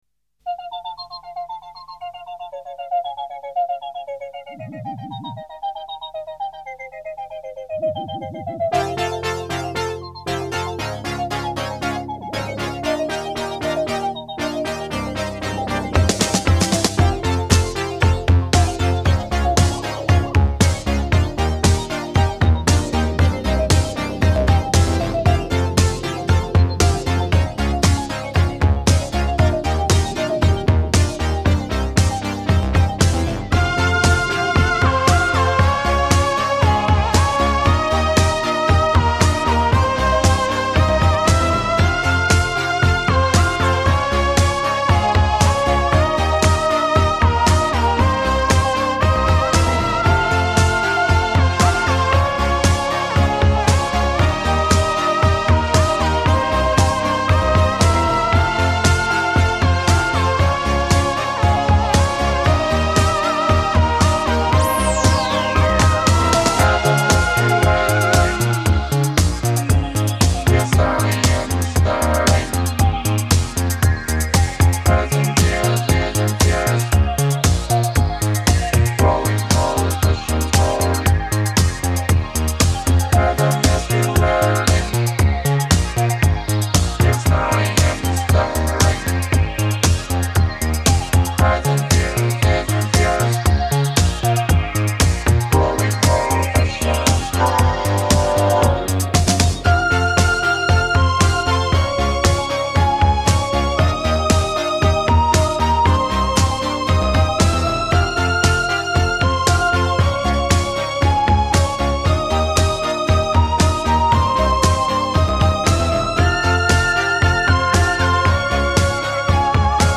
synthtastic
Japanese Electropop >> Disco Michael >> Crappy Clapton.